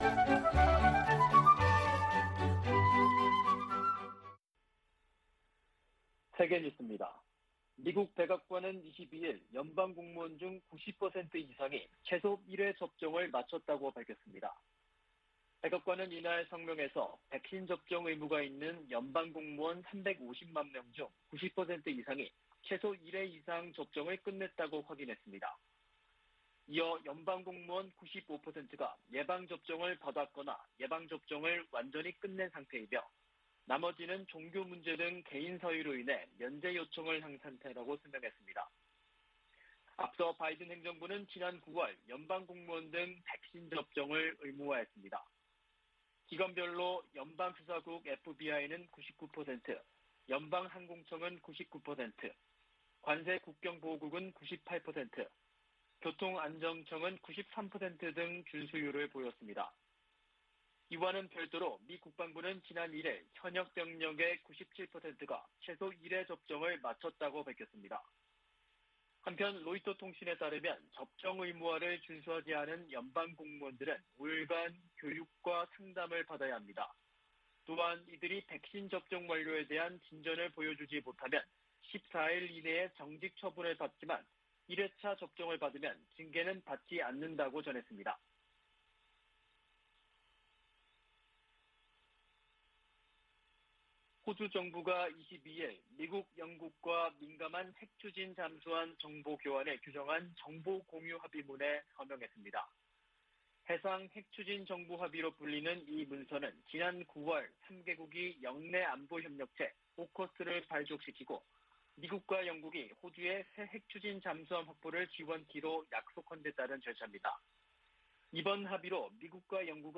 VOA 한국어 아침 뉴스 프로그램 '워싱턴 뉴스 광장' 2021년 11월 23일 방송입니다. 바이든 미국 대통령의 베이징 동계올림픽 외교적 보이콧 검토에 따라 한국 정부의 한반도 평화프로세스 구상에 어떤 영향이 미칠지 주목되고 있습니다. 커트 캠벨 백악관 국가안보회의 인도태평양 조정관은 바이든 정부 인도태평양 전략의 핵심 중 하나로 동맹과의 심도 있는 협력을 꼽았습니다. 미국 바이든 행정부가 인도태평양 지역에서 동맹국의 역할을 강조하며 중국 견제를 위한 움직임을 이어가고 있습니다.